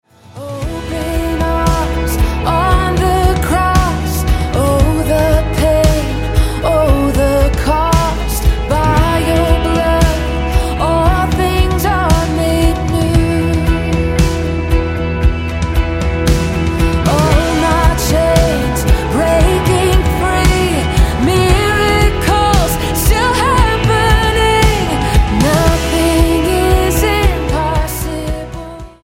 STYLE: Pop
characteristically powerful vocals take centre stage here